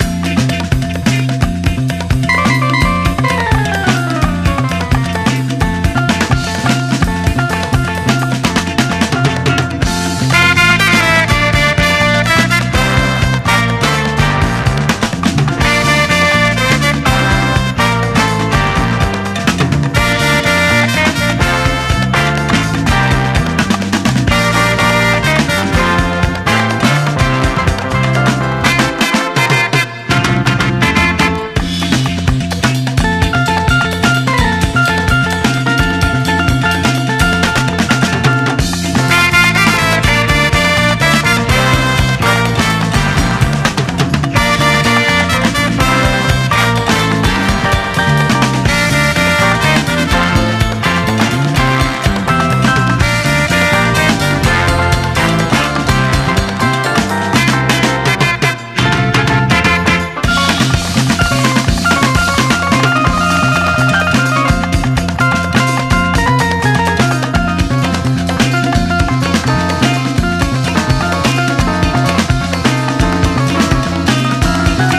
パキッとした硬質サウンドをバックに熱く咆哮する、セルフ・プロデュースのファンキー・アルバム！